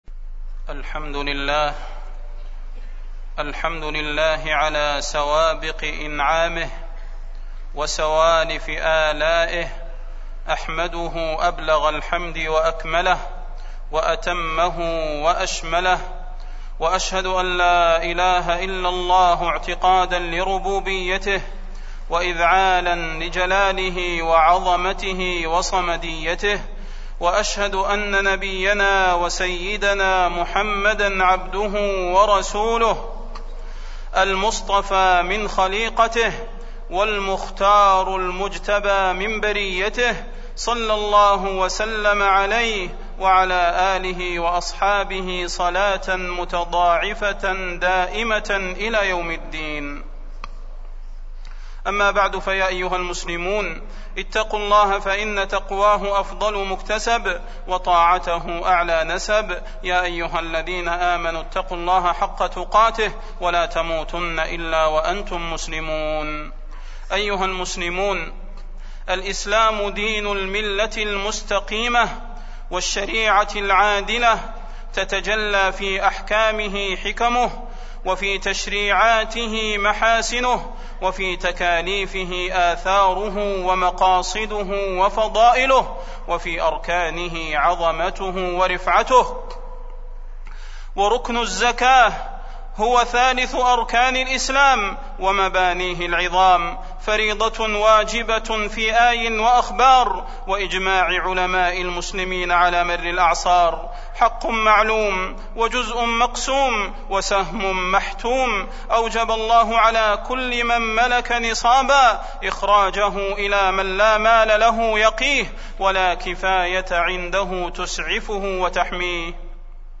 تاريخ النشر ١٩ شعبان ١٤٢٦ هـ المكان: المسجد النبوي الشيخ: فضيلة الشيخ د. صلاح بن محمد البدير فضيلة الشيخ د. صلاح بن محمد البدير وجوب الزكاة